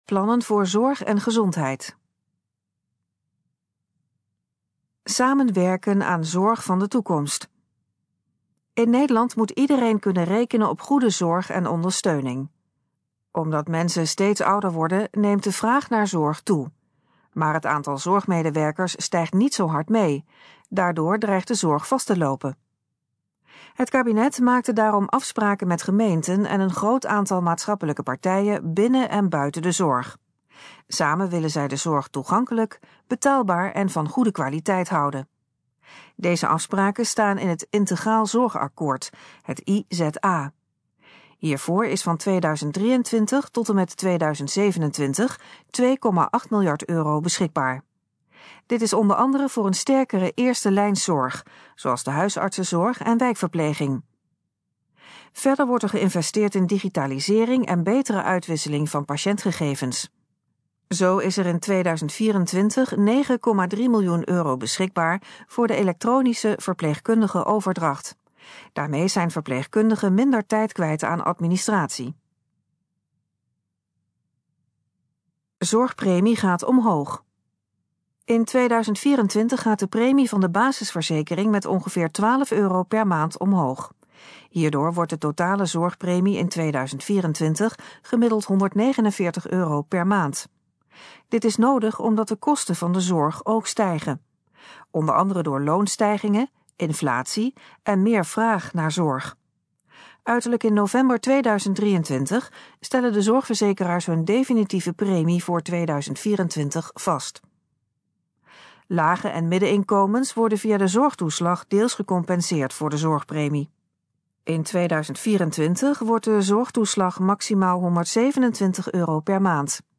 Gesproken versie van Plannen voor Zorg en gezondheid
In het volgende geluidsfragment hoort u meer informatie over de plannen voor de zorg en gezondheid. Het fragment is de gesproken versie van de informatie op de pagina Plannen voor Zorg en gezondheid.